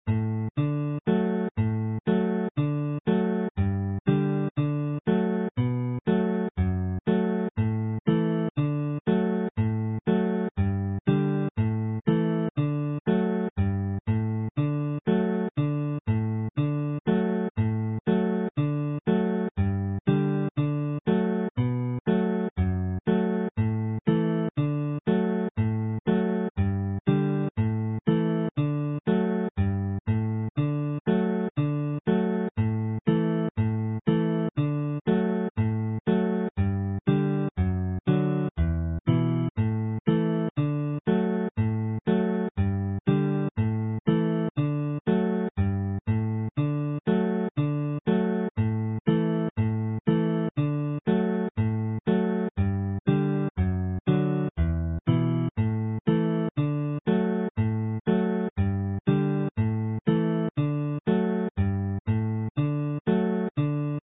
Hornpipes are played with a skip - dotted - but written in plain timing, like a reel, with the understanding that they can be interpreted either as a reel or a hornpipe.
Slow sound versions of the files are also included to make the tunes easier to learn by ear.